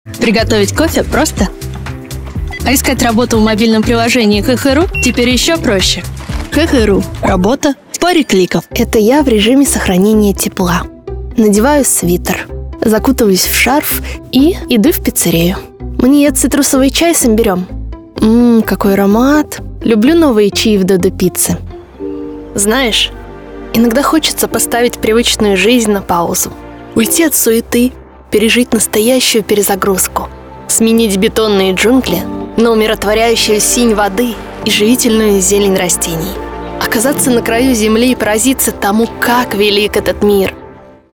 Нежный, жизнерадостный, романтичный, высокий и молодой голос. Особенно специализируюсь на нативной, естественной подаче.
Тракт: Микрофон: Neumann TLM-103 Обработка: Long VoiceMaster Звуковая карта: SSL 2+ Акустическая кабина